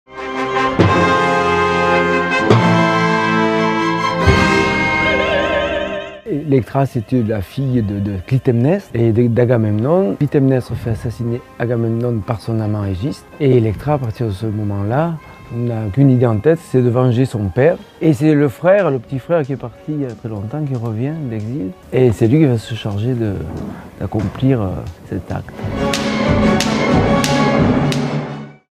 On entre en effet dans une veine absolument expressionniste que Strauss ne retrouvera plus dans ses opéras suivants. Une fois encore, il illustre à merveille la violence de son livret, dans un écrin orchestral somptueux qui ouvre par moments la porte à l'atonalité.
Chez Strauss ce sont les voix féminines qui sont à l'honneur.